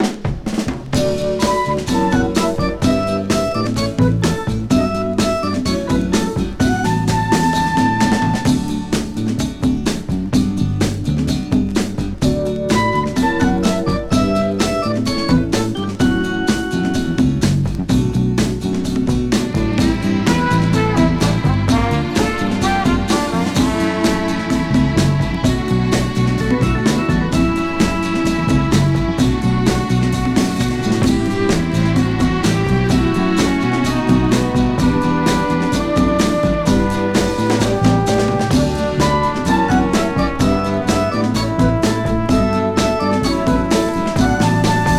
めくるめく美メロ連発の演奏に誘われ、良質さに打たれます。
Pop　USA　12inchレコード　33rpm　Stereo